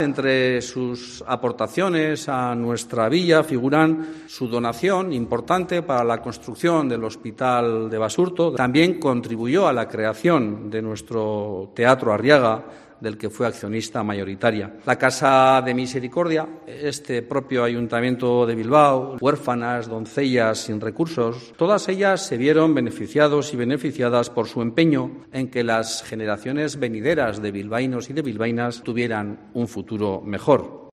Juan Mari Aburto, Alcalde de Bilbao destaca las aportaciones de Doña Casilda a la Villa